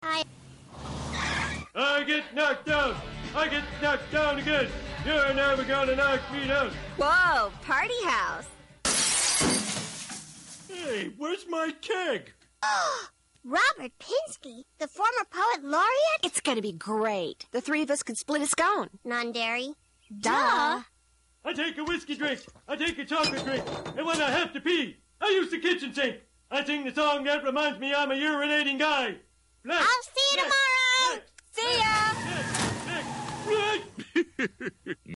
Here's a slightly modified version of the song: